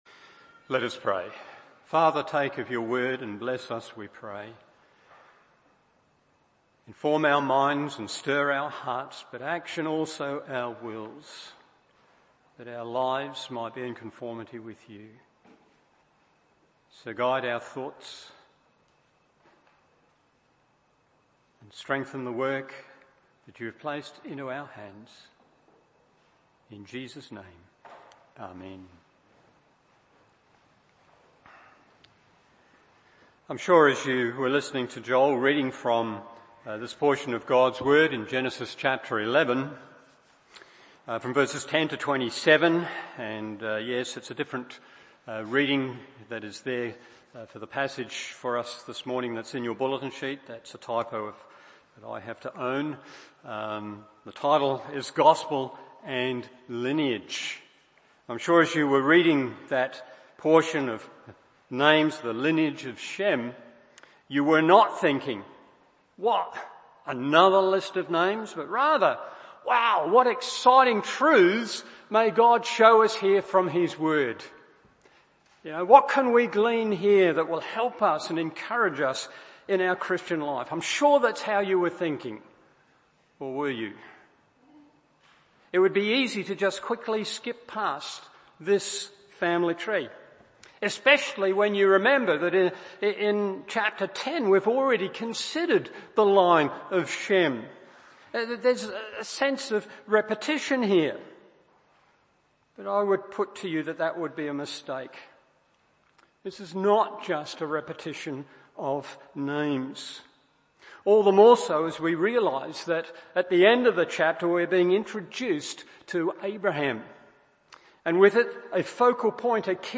Morning Service Genesis 11:10-32 1. Gospel Opportunity 2. Gospel Realism 3.